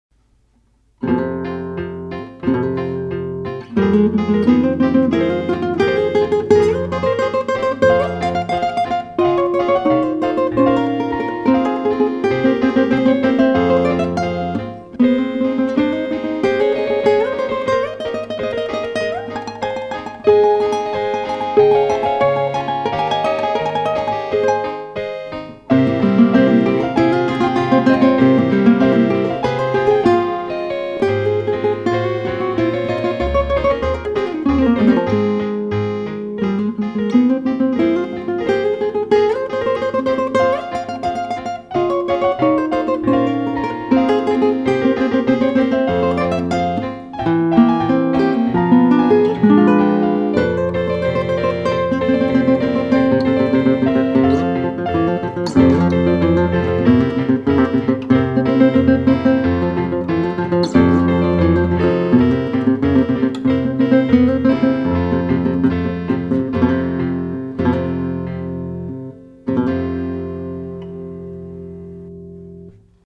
Classical Guitar
An improvised duet based on a tune you'll recognize.